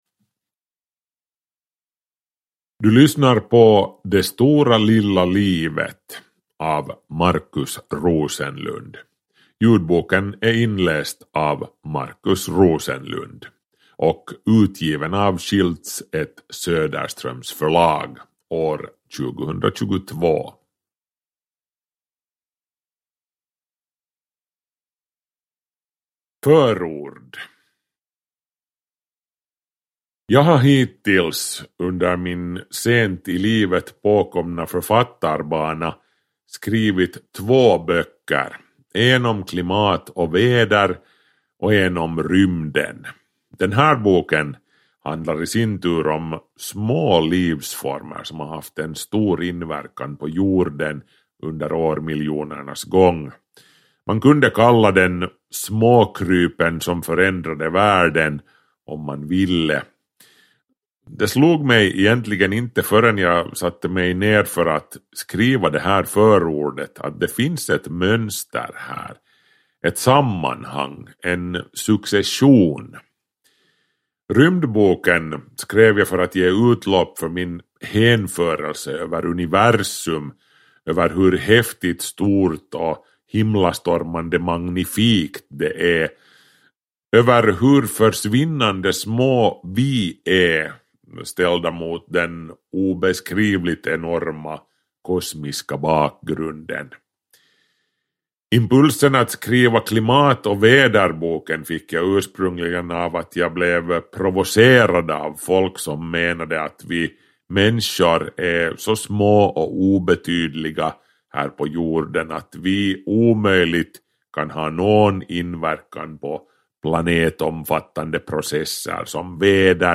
Det stora lilla livet – Ljudbok – Laddas ner